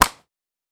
Foley Sports / Hockey / Ball Hit Powerful.wav
Ball Hit Powerful.wav